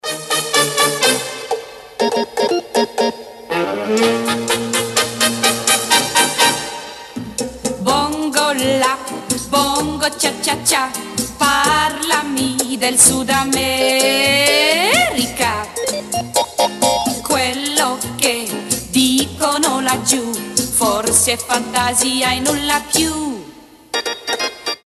веселые
итальянские
труба
джаз
60-е